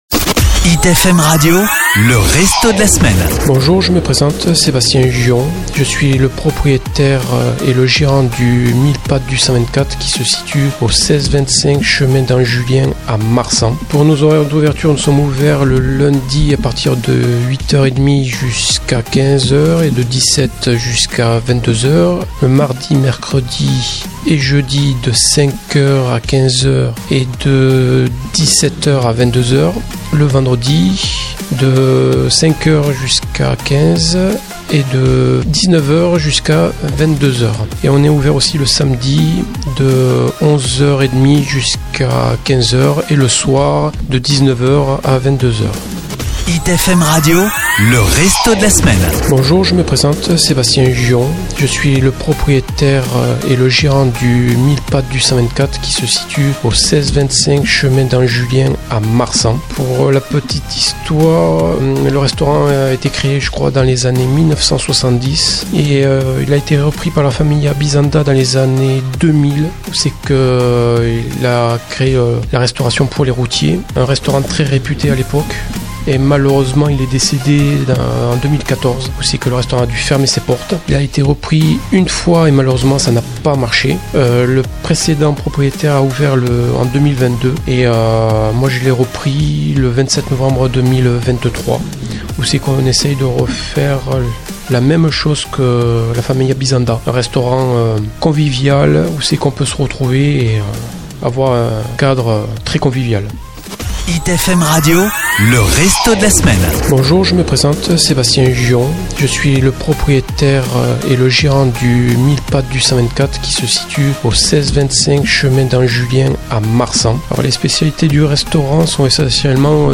Nous vous proposons de retrouver l'interview complète du restaurant de la semaine grâce à ce podcast, où le chef vous présente son restaurant, ses spécialités culinaires, les producteurs locaux avec lesquels il travaille, quelques insolites et histoires et bien d'autres choses.